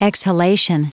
Prononciation du mot exhalation en anglais (fichier audio)
Prononciation du mot : exhalation